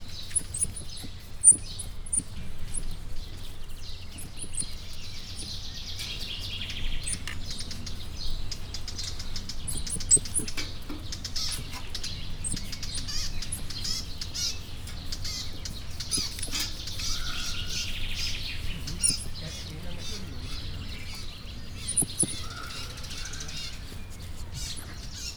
Directory Listing of /_MP3/allathangok/miskolcizoo2018_professzionalis/ormanyos_medve/
hatsotraktus_miskolczoo0025.WAV